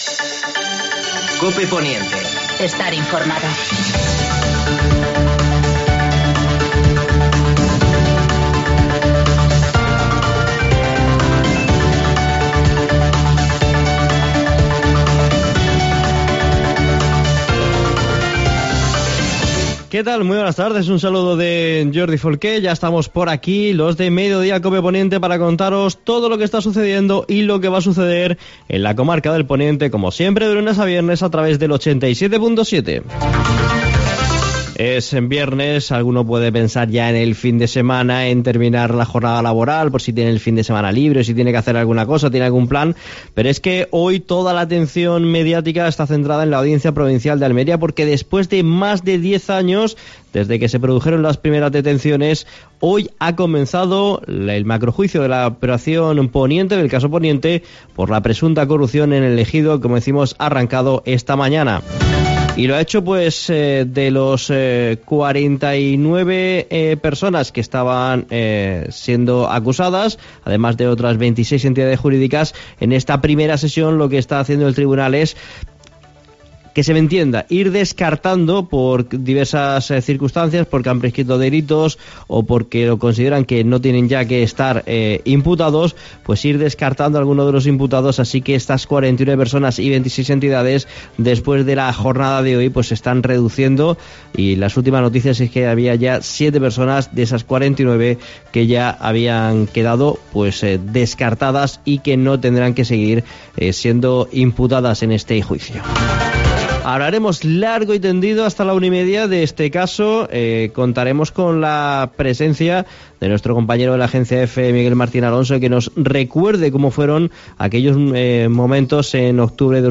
Entrevista al alcalde de El Ejido (Francisco Góngora).